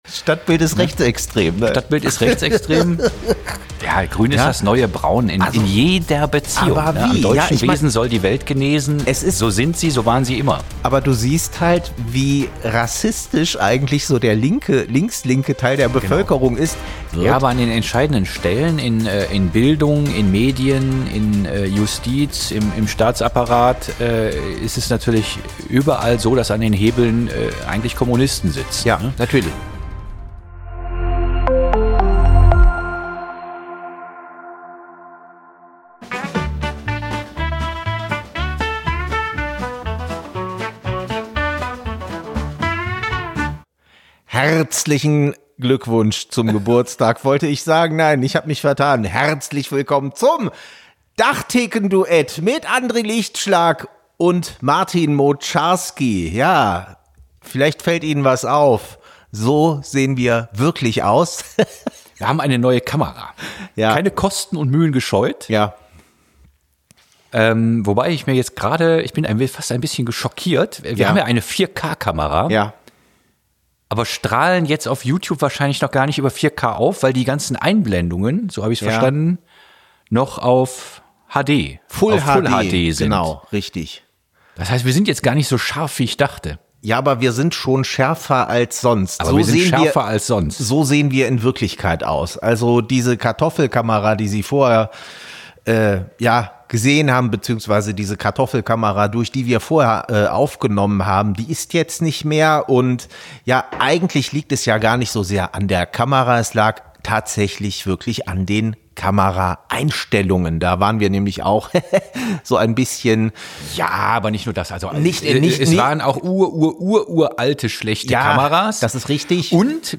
Kleine Redaktionskonferenz am Tresen
Wie immer werden in dieser Reihe an der ef-Theke spontan aktuelle Entwicklungen und brennende Themen beleuchtet.